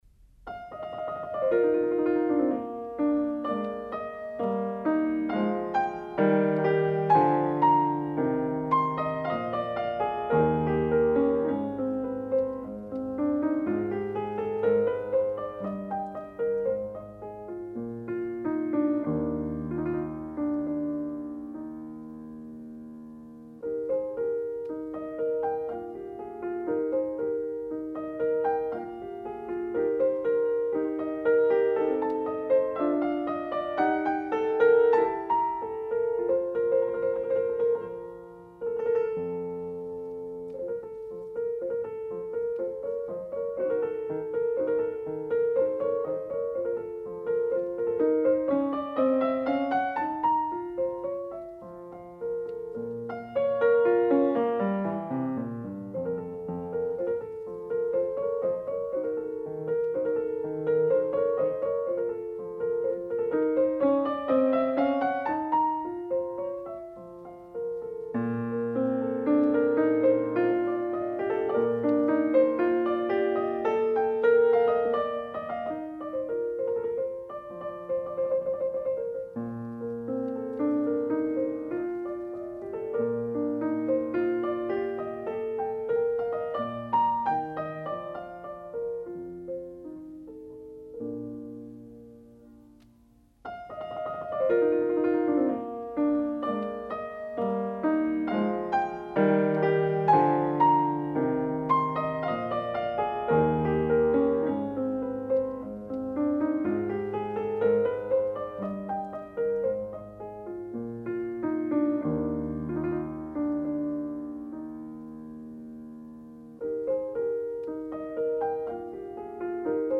Сонаты для фортепиано.